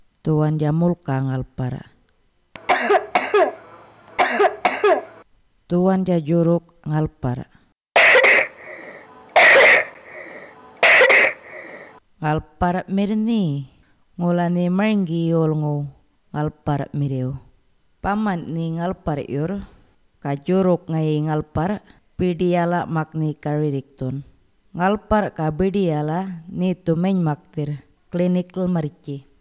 Cough Audio – Djambarr
20048_wet-&-dry-cough-final-djambarr.wav